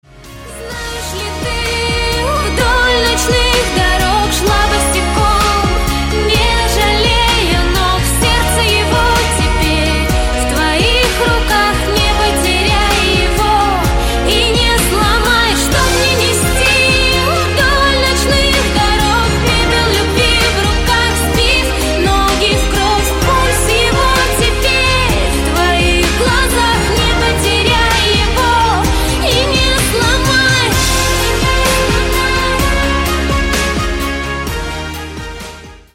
Танцевальные рингтоны
поп
грустные